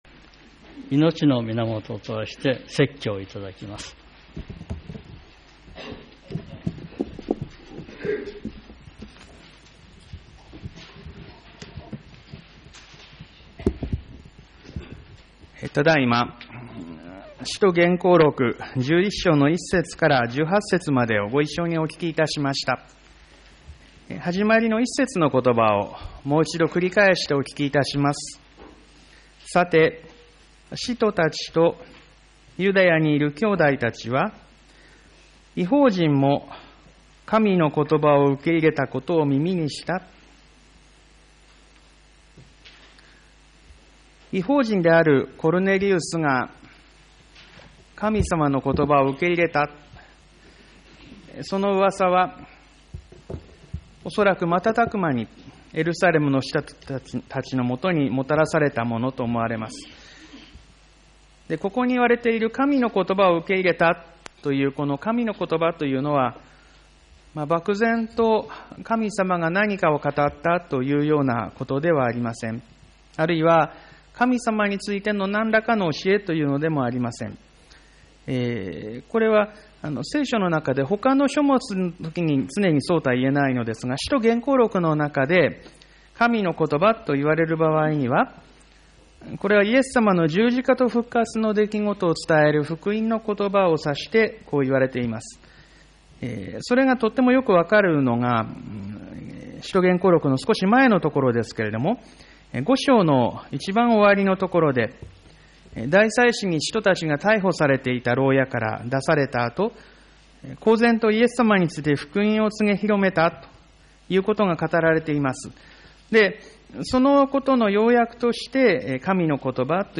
■ ■ ■ ■ ■ ■ ■ ■ ■ 2020年1月 1月5日 1月12日 1月19日 1月26日 毎週日曜日の礼拝で語られる説教（聖書の説き明かし）の要旨をUPしています。